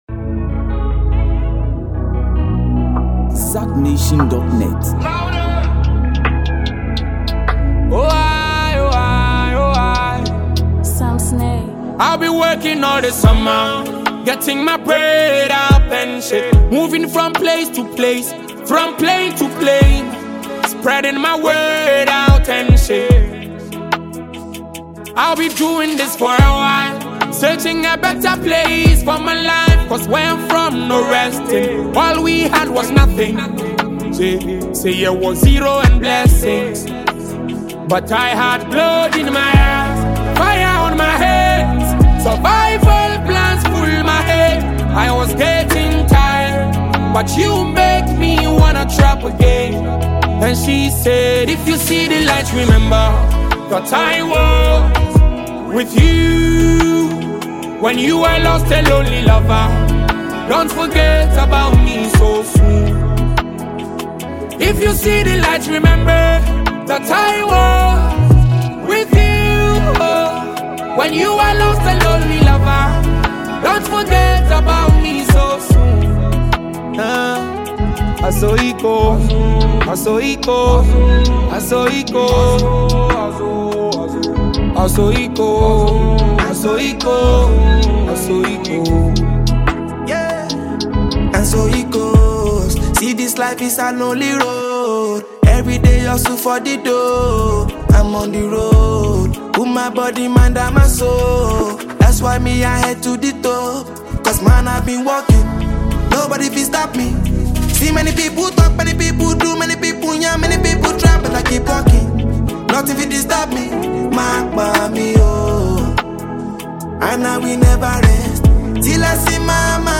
fun and upbeat